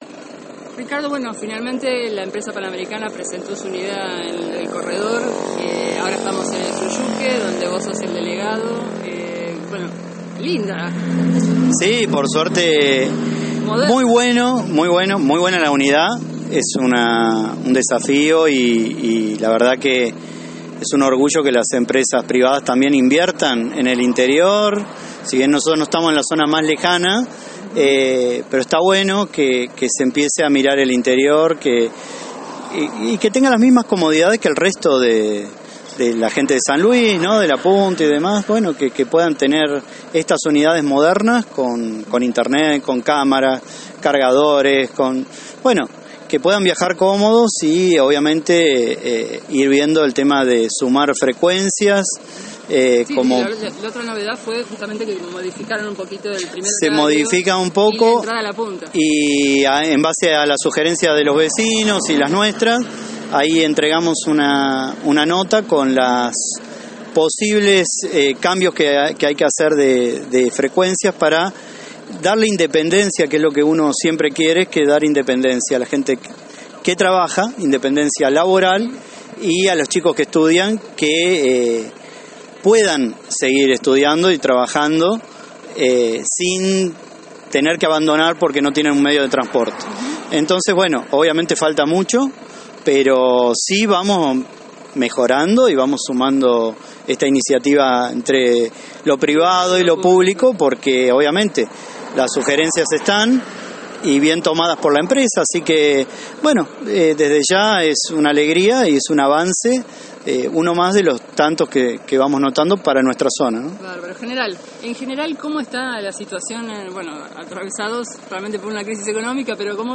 En dialogo con la Posta, Soroka celebró que los vecinos de la zona puedan viajar con mayor comodidad y enfatizó: «Es un orgullo que las empresas privadas también inviertan en el interior».